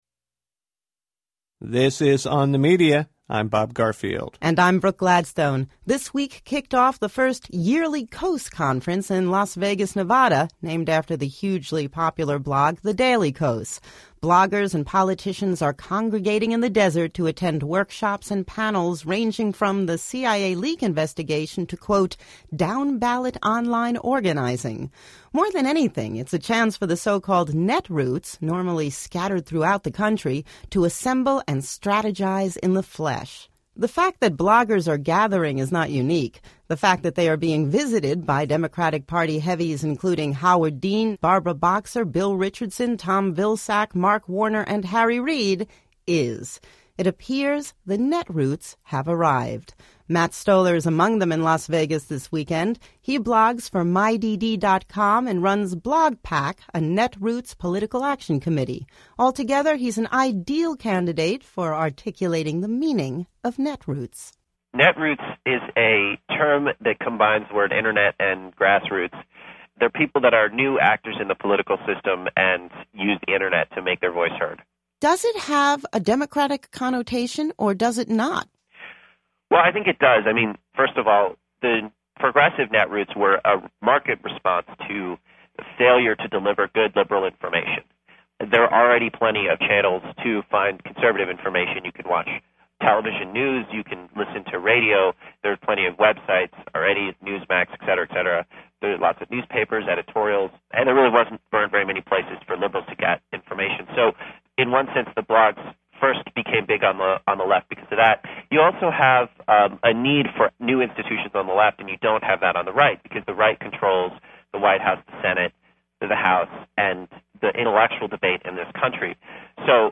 These questions were discussed in the On The Media radio show in June 2006.